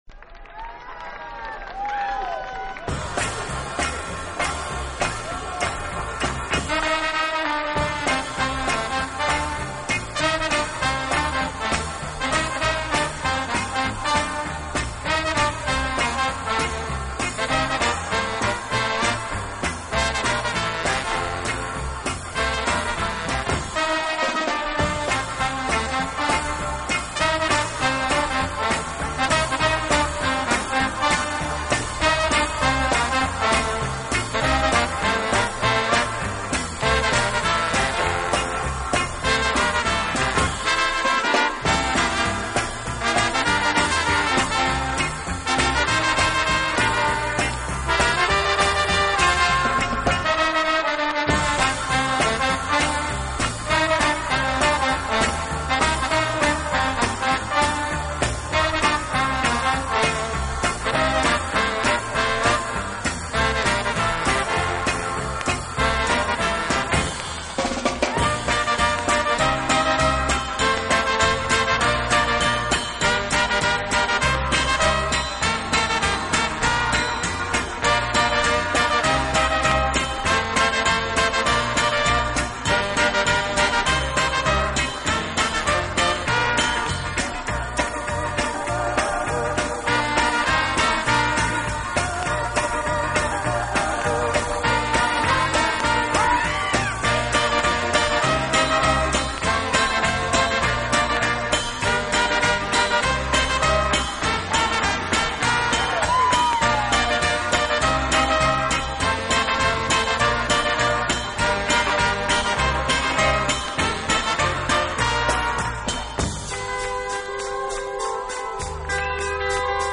此外，这个乐队还配置了一支训练有素，和声优美的伴唱合唱队。